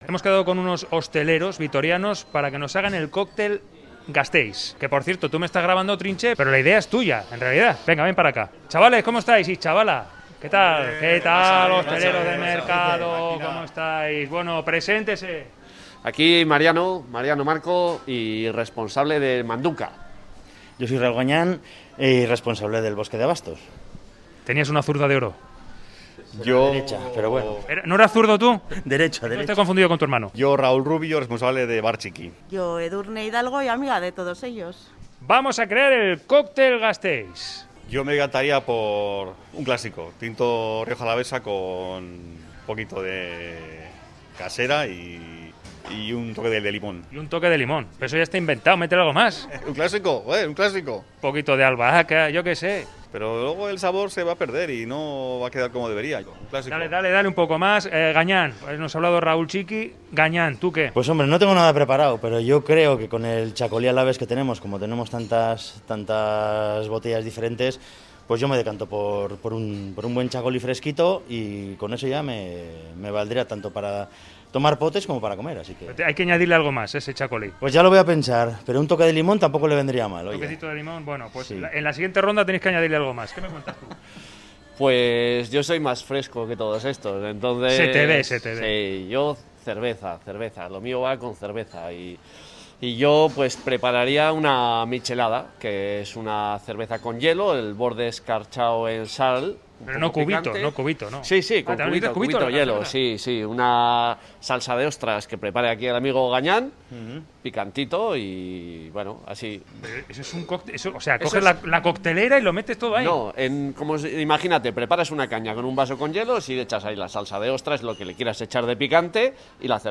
Audio: Reportaje: A por el 'Cóctel Gasteiz'